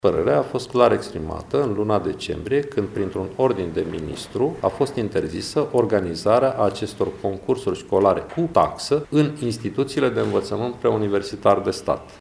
Precizarea a fost făcută de ministrul Educaţiei Naţionale, Sorin Cîmpeanu, care a fost întrebat la Tîrgu-Mureş despre concursurile şcolare care au ajuns în atenţia procurorilor.